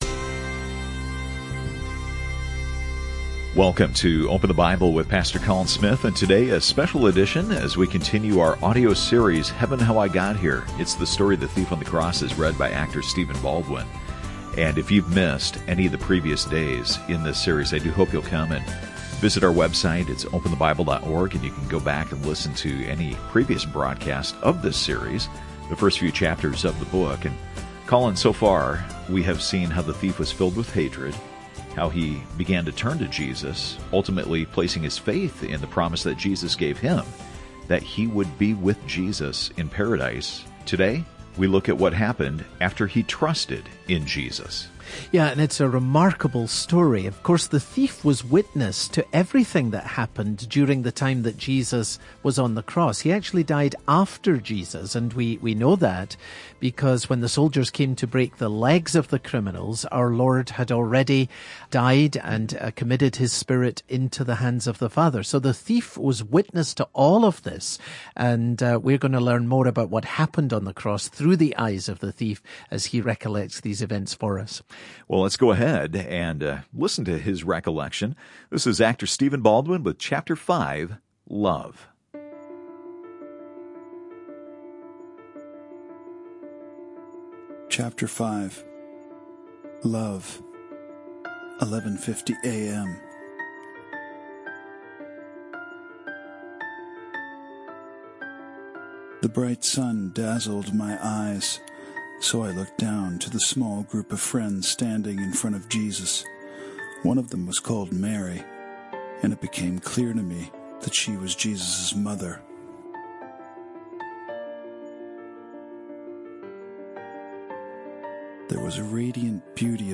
This radio broadcast features narration by actor Stephen Baldwin.